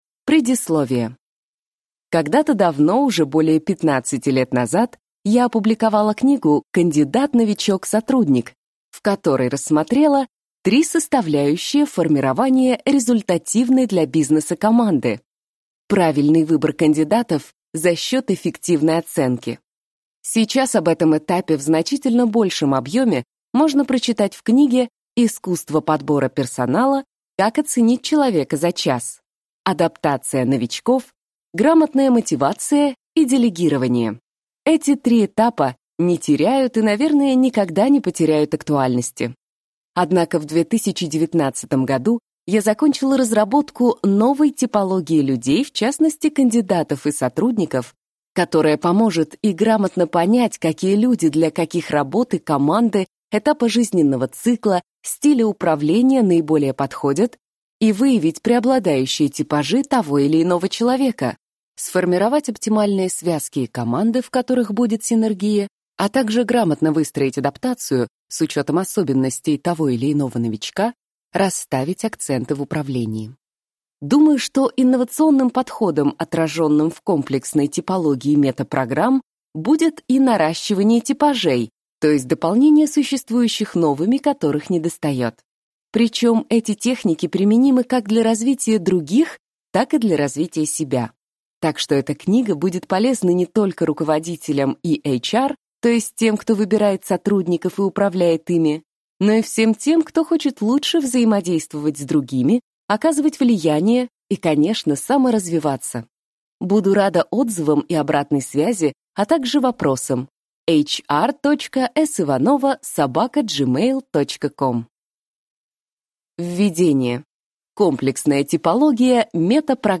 Аудиокнига Кандидат. Новичок. Сотрудник | Библиотека аудиокниг